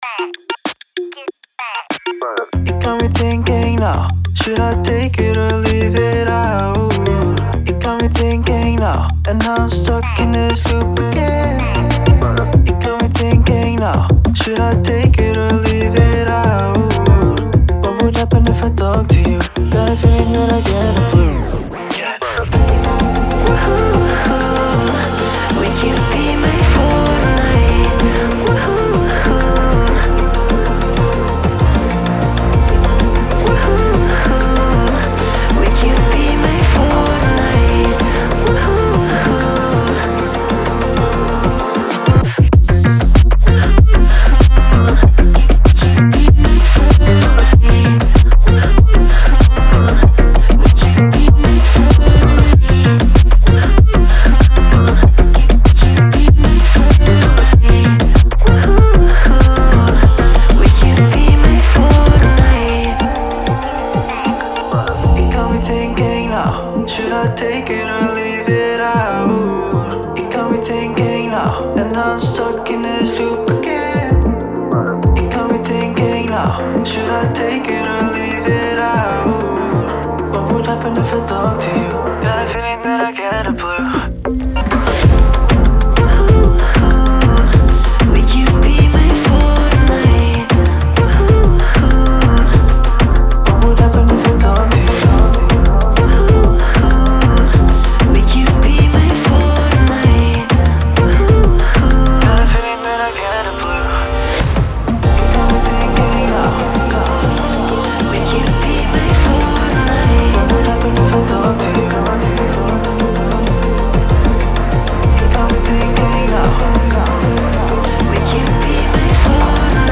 Electronic, Energetic, Restless, Hopeful, Quirky